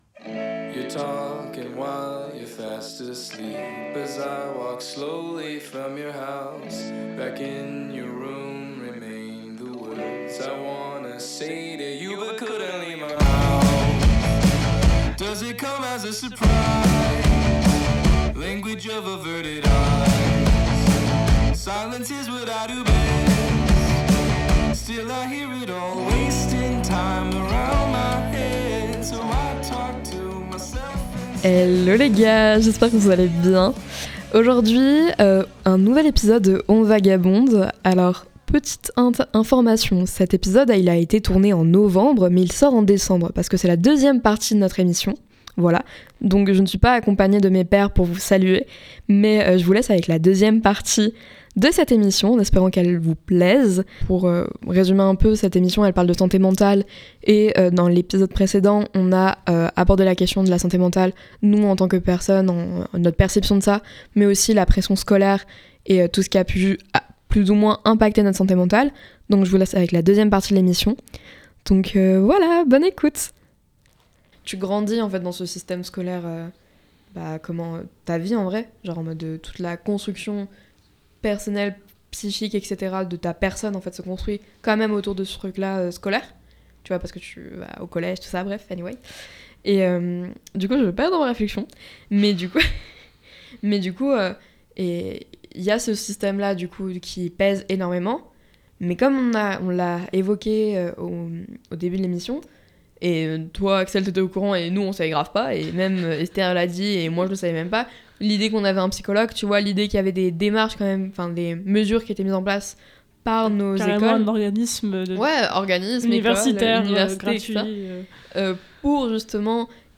Le concept est simple : une discussion comme celle qu’on a avec ses potes tard dans la nuit, quand les masques tombent et qu’on laisse nos esprits vagabonder.
C’est un espace où l’on réfléchit, débat, on rigole (beaucoup aussi), tout en partageant nos perspectives sur ce qui nous intrigue ou nous passionne.